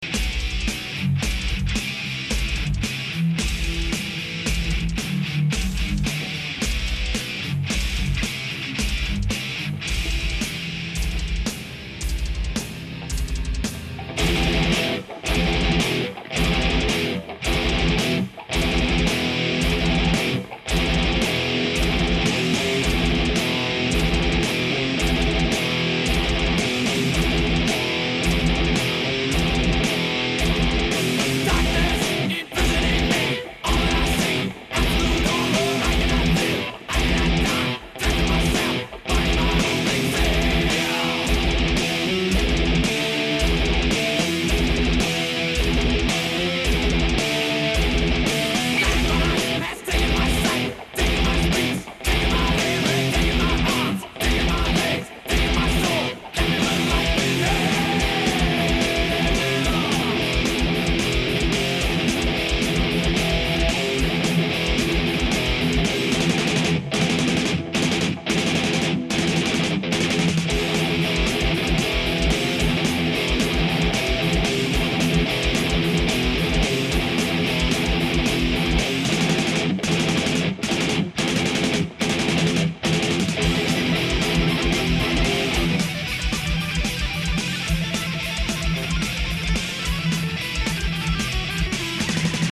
Вниз  Играем на гитаре